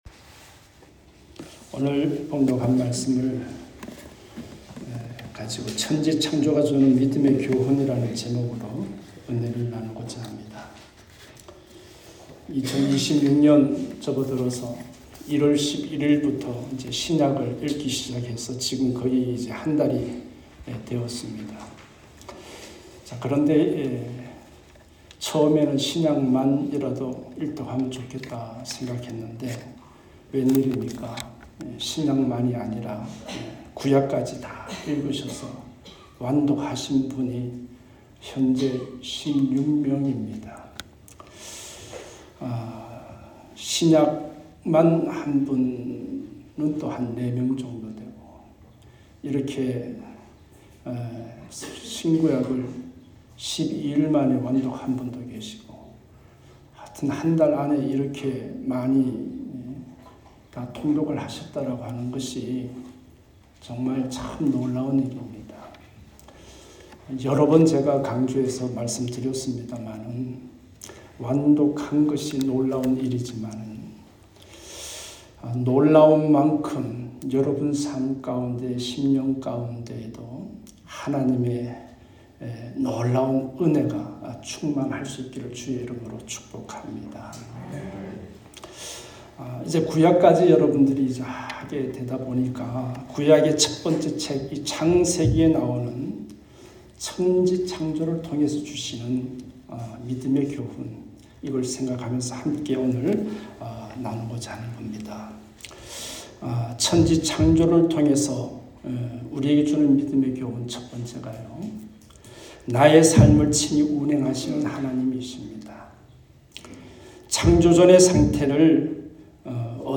주일음성설교 에 포함되어 있습니다.